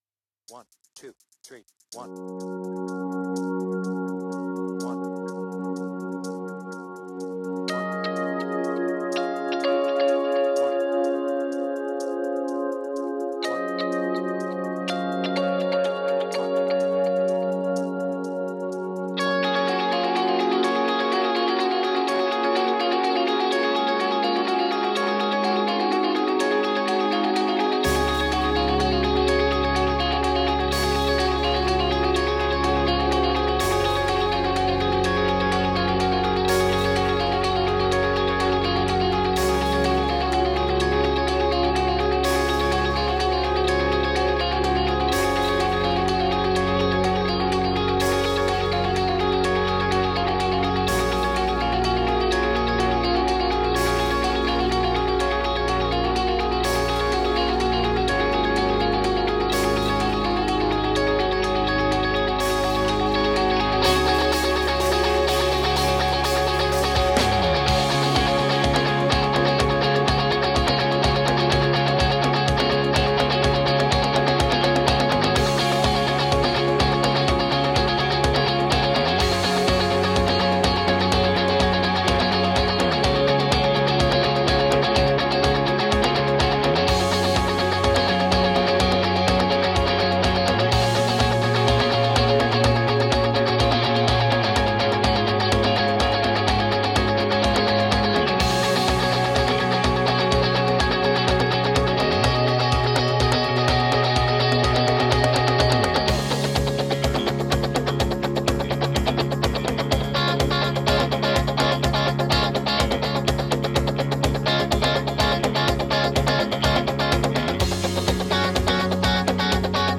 BPM : 125
Tuning : Eb
Without vocals